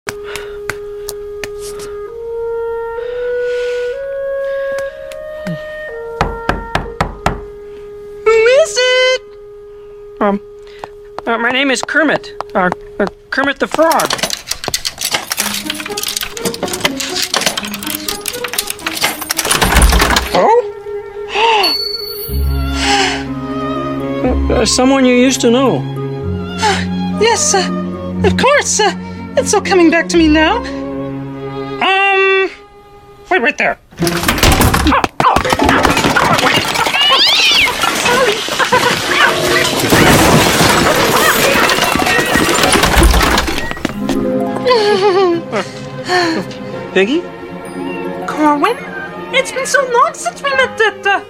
Now with added sound effects.